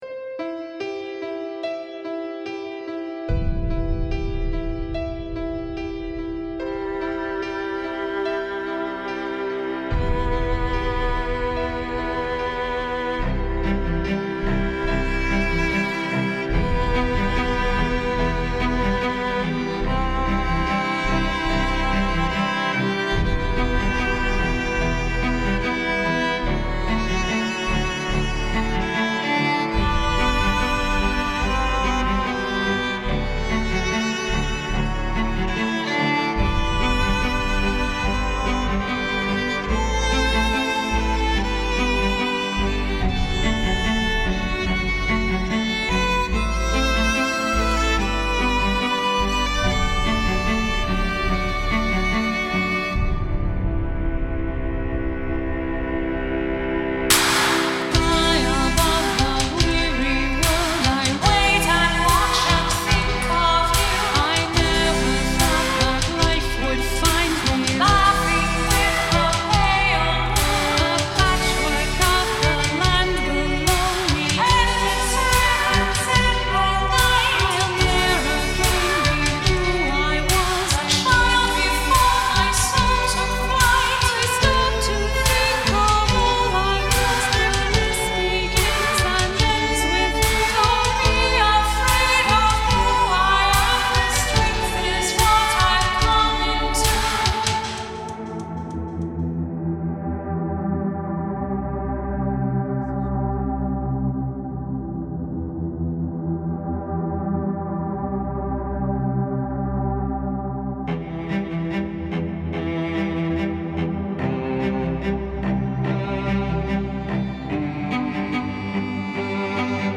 Жанр обощенно зовется стимпанк-мьюзик.